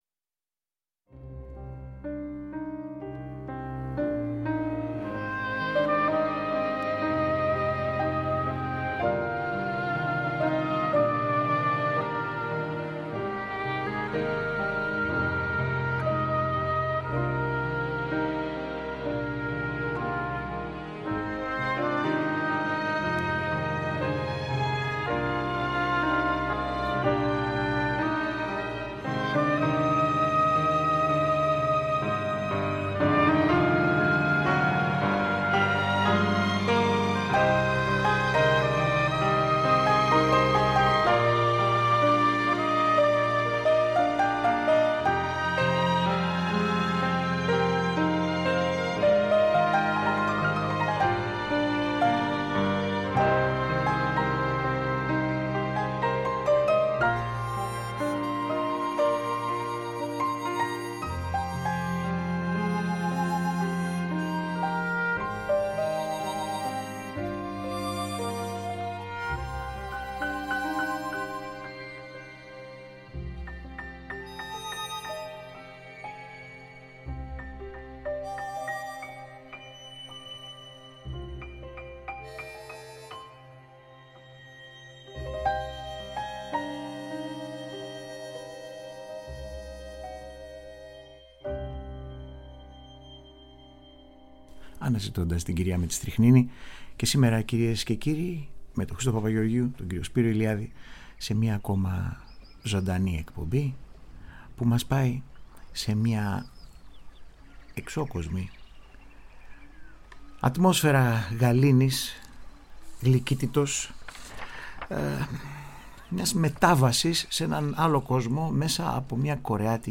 Μια βαθιά διαλογιστική μουσική
Soundtrack